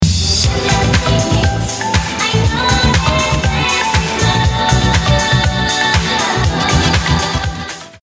followalert.mp3